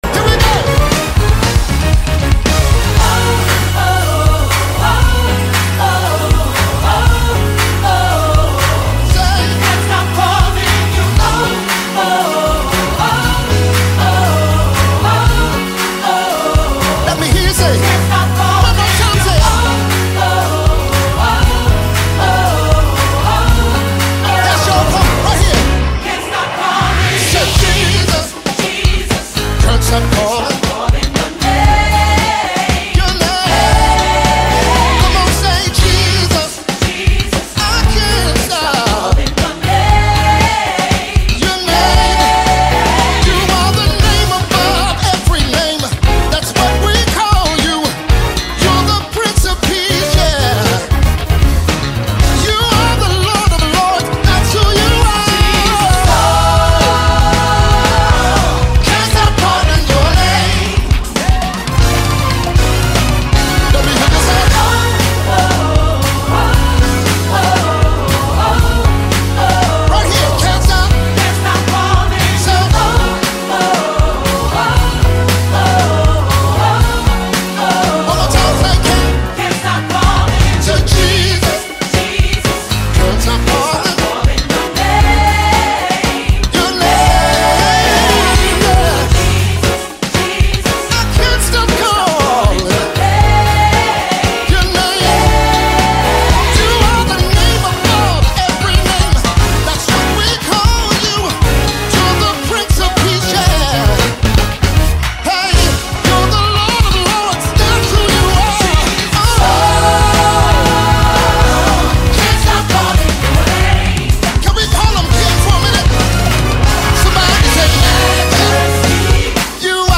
Mp3 Gospel Songs